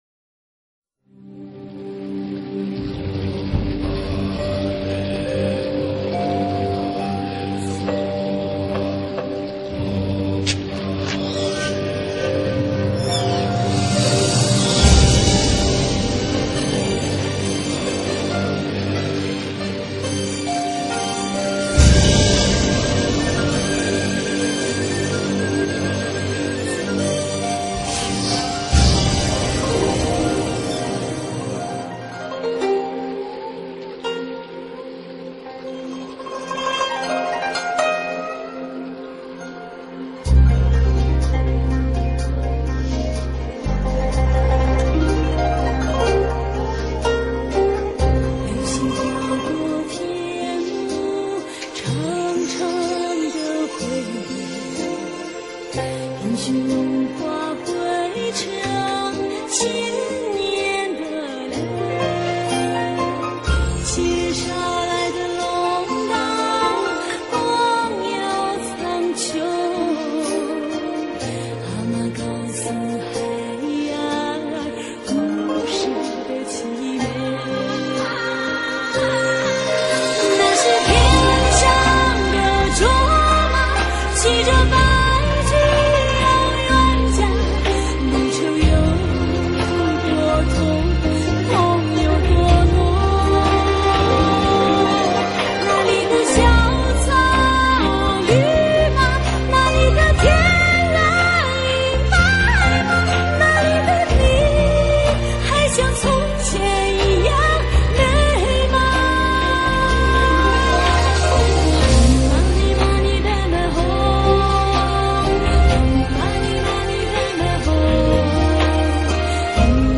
她的音乐继承了民族艺术的精华，又充满浓厚的现代气息。